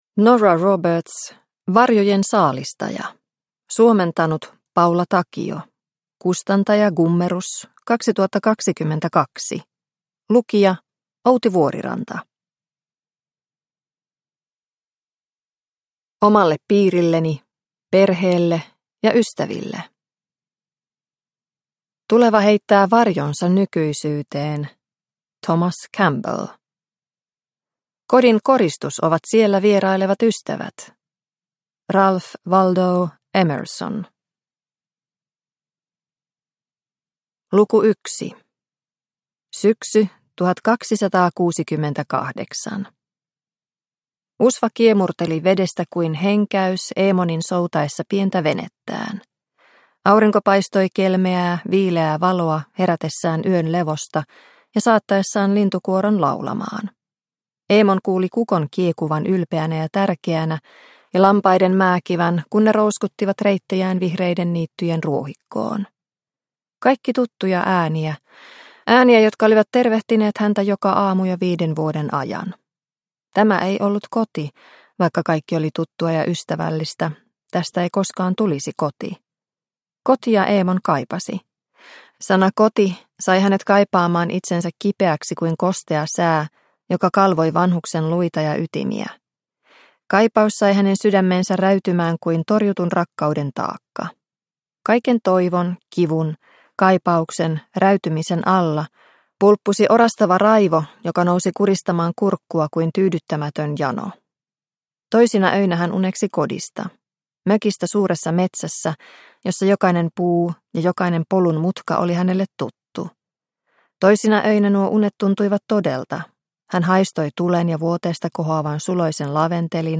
Varjojen saalistaja – Ljudbok – Laddas ner